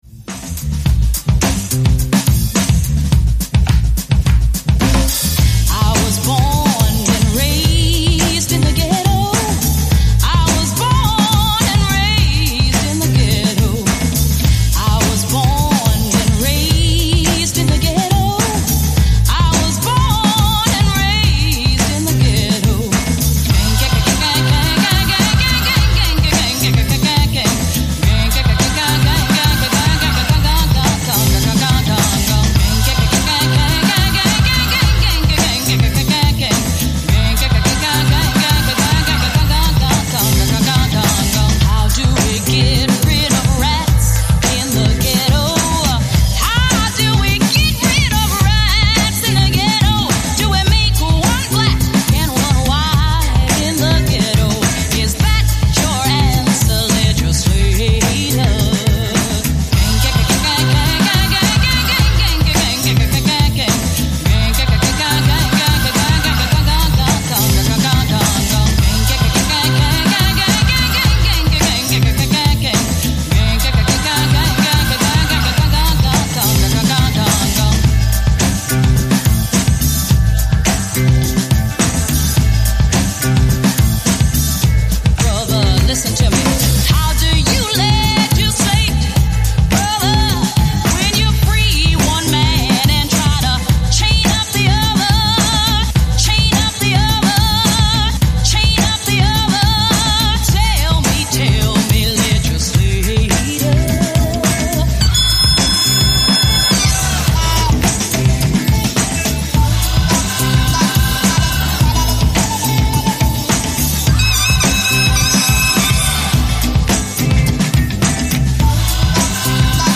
フロアを明るく賑わしてくれそうな一枚